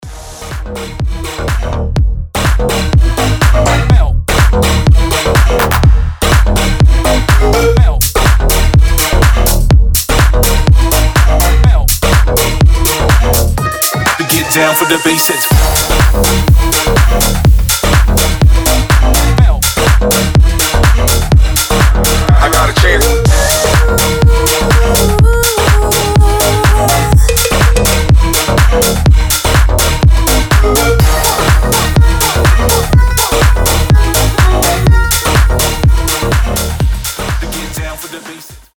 • Качество: 320, Stereo
громкие
жесткие
Electronic
EDM
мощные басы
качающие
взрывные
Стиль: future house